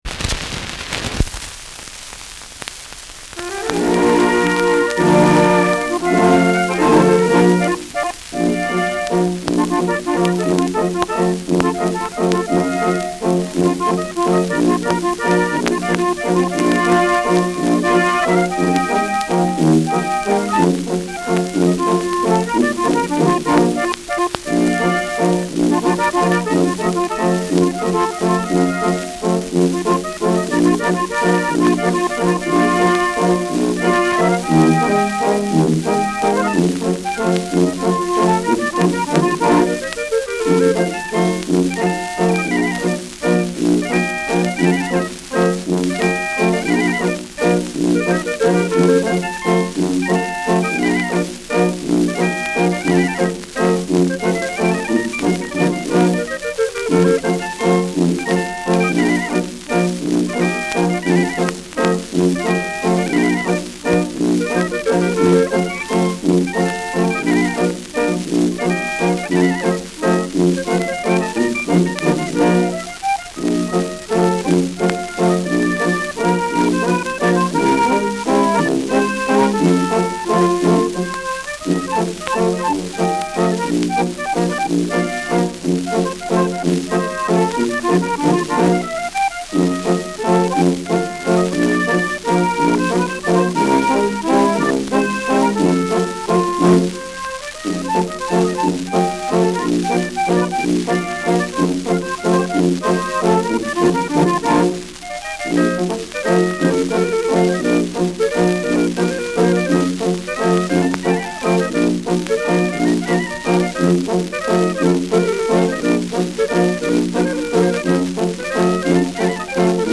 Schellackplatte
Abgespielt : Durchgehend stärkeres Knacken
Kapelle Jais (Interpretation)
[München] (Aufnahmeort)